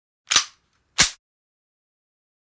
WEAPONS - HANDGUNS 9 MM: INT: Cocking, single breech pull back and release, slight room reverb.